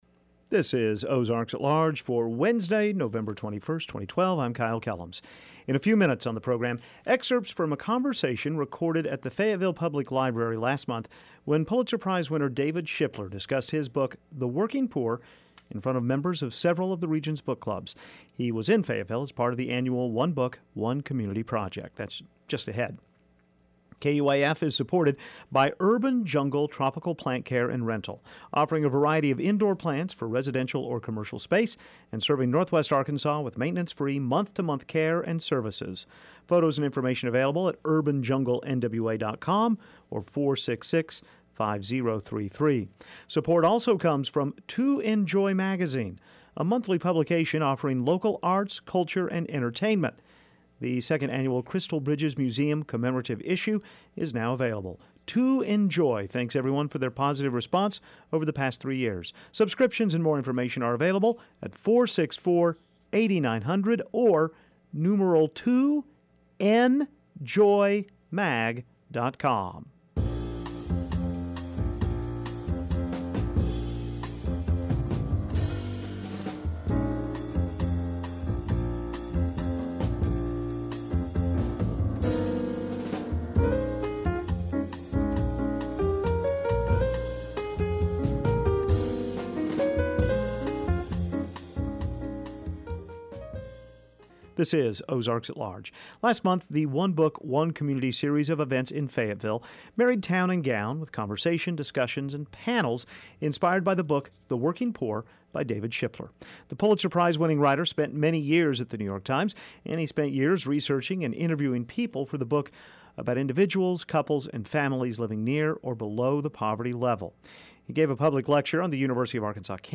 Audio: oalweb112112.mp3 Ahead on this edition of Ozarks: excerpts from a conversation recorded at the Fayetteville Public Library last month when Pulitzer Prize winner David Shipler discussed his book “The Working Poor” in front of members of several of the regions book clubs. He was in Fayetteville as part of the annual One Book-One Community project.